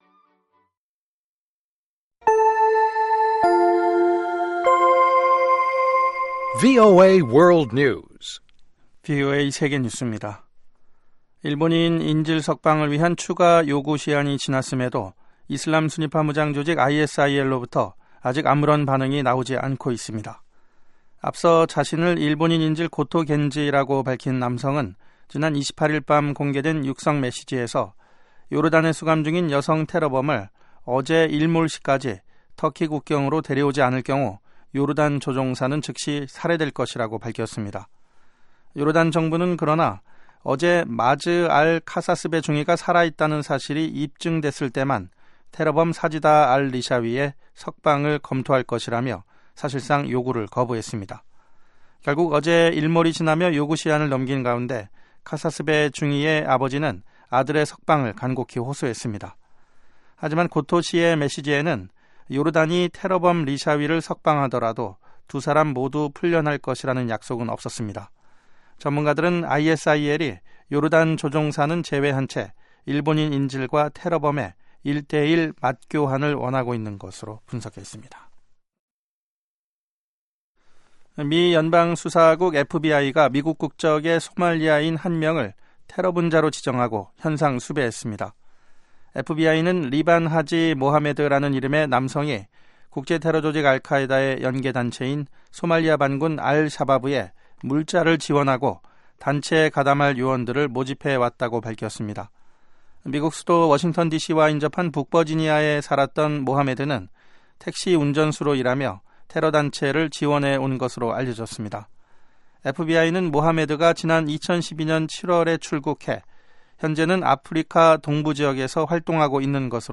VOA 한국어 방송의 간판 뉴스 프로그램 '뉴스 투데이' 2부입니다. 한반도 시간 매일 오후 9시부터 10시까지 방송됩니다.